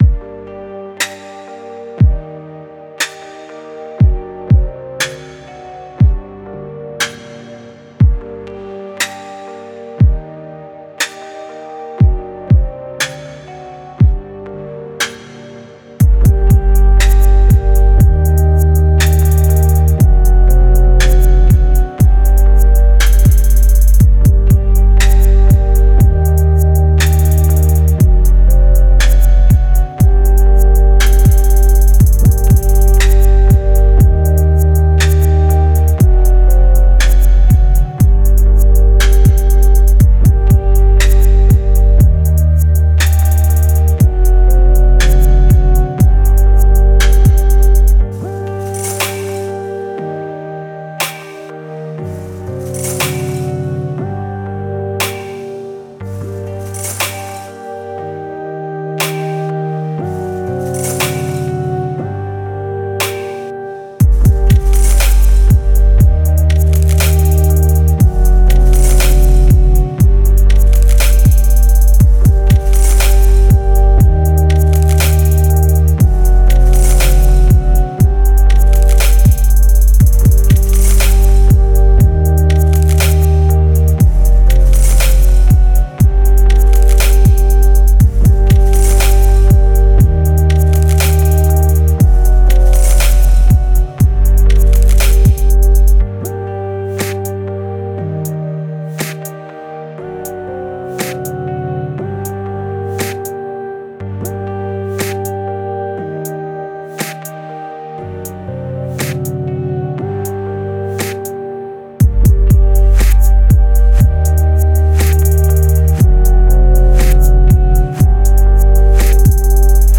Type: Samples
Chill Out / Lounge Multi-genre
Includes tempo-labeled loops and samples like pounding kicks, tight and percussive claps, classic hats, huge crashes, clean snaps, tough snares, and creative percussion elements - these will make your tracks professional.